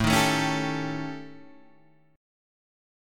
A 7th Flat 5th